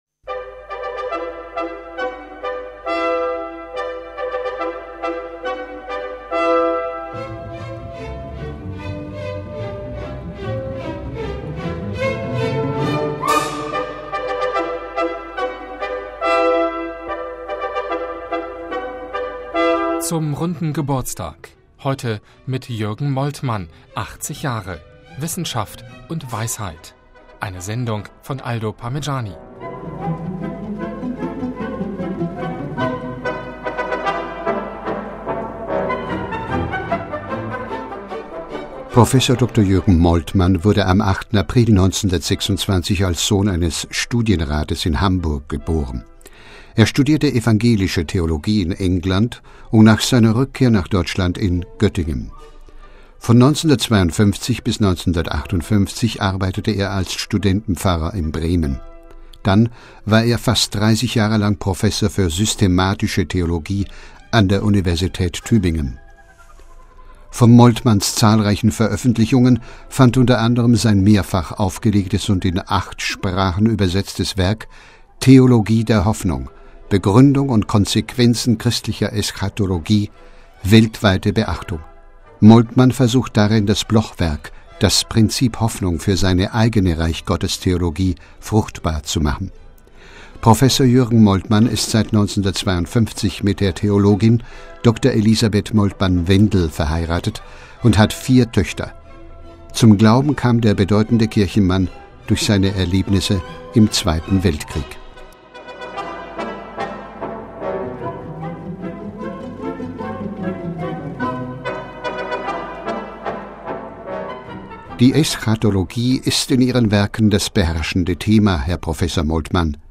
Audio-Special: Großes Interview mit Jürgen Moltmann (80)